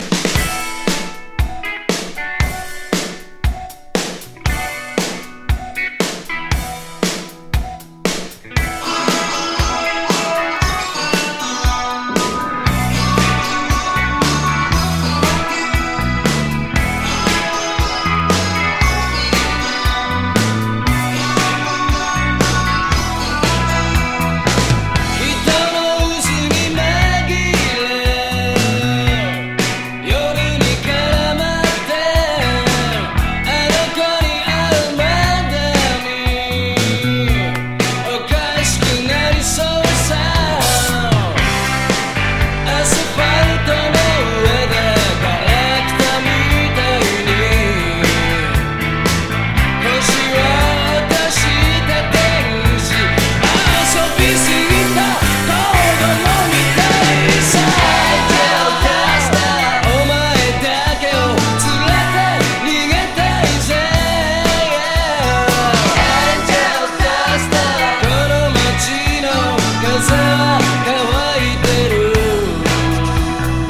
和レアリック・ロッカー